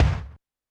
Drums_K4(49).wav